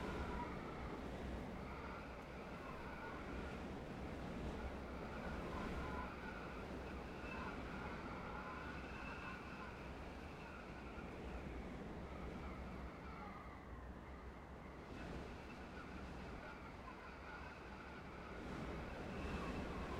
sfx_amb_map_zoomedout_mountain.ogg